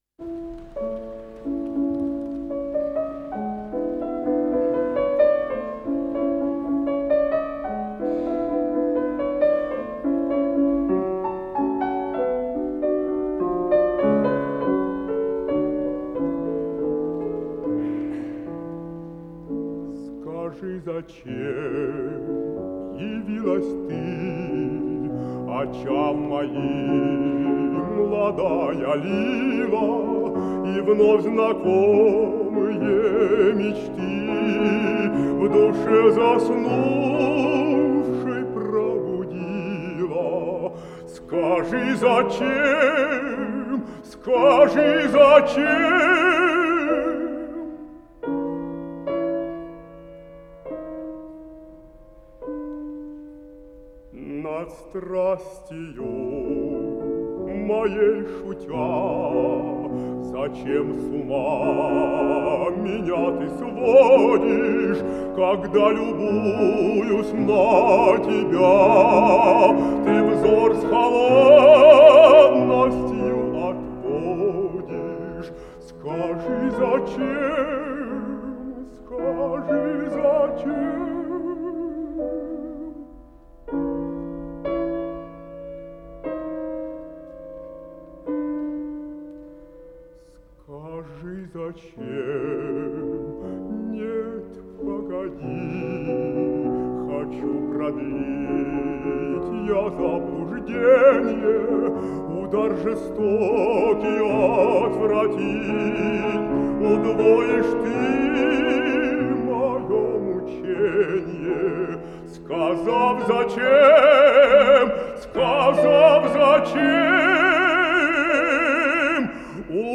Жанр: Вокал
ф-но
Большой зал Консерватории
баритон